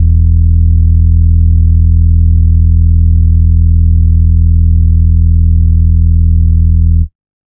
Bass (8).wav